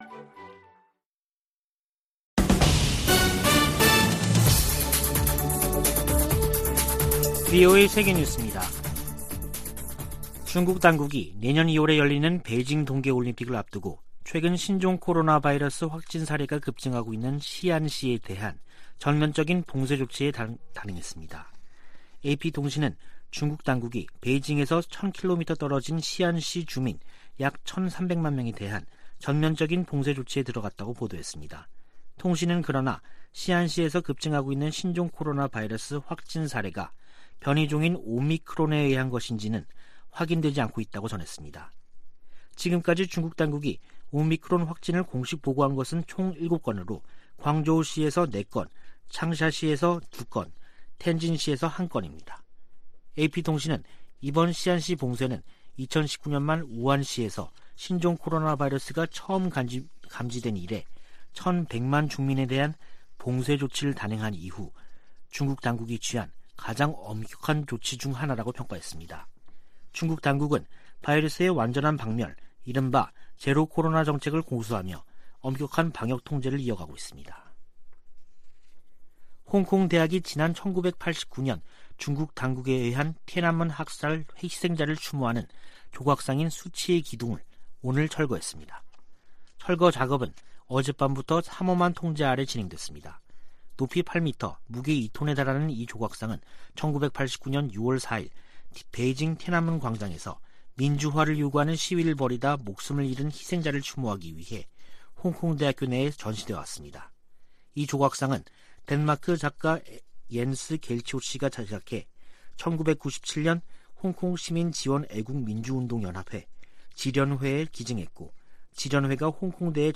VOA 한국어 간판 뉴스 프로그램 '뉴스 투데이', 2021년 12월 23일 3부 방송입니다. 미 의회가 올해 처리한 한반도 외교안보 관련안건은 단 한 건이며, 나머지는 내년으로 이월될 예정입니다.